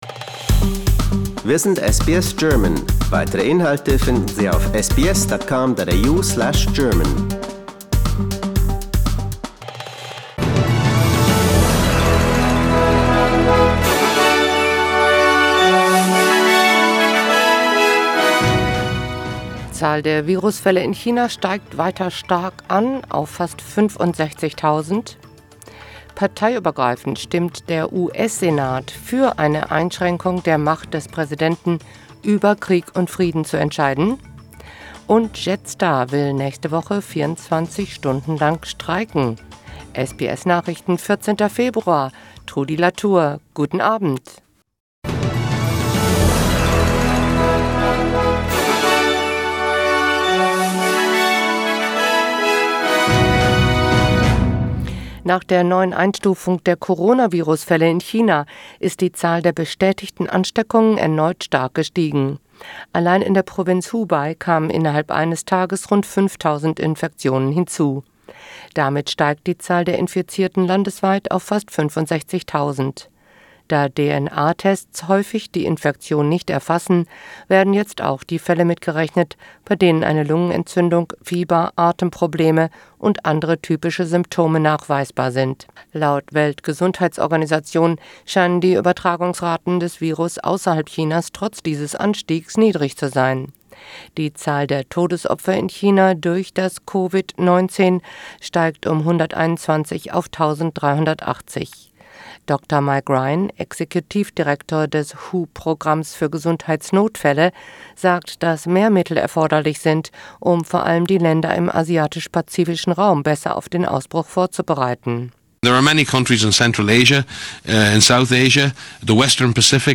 SBS Nachrichten, Freitag 14.02.2020